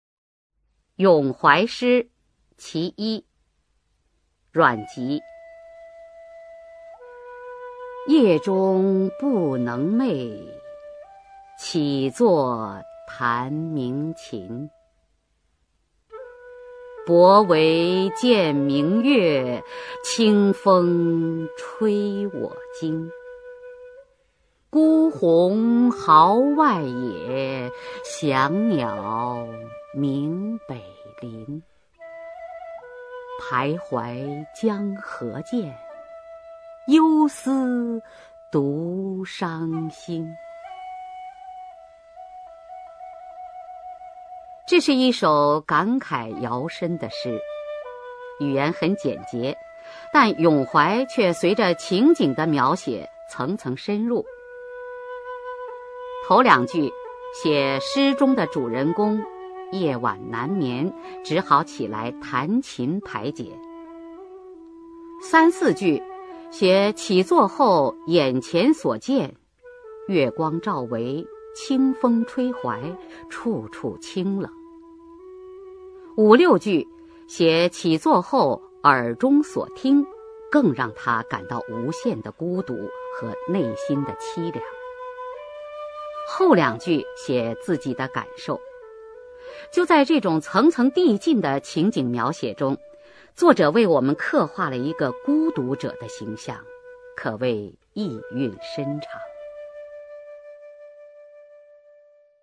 [魏晋诗词诵读]阮籍-咏怀（夜中） 古诗朗诵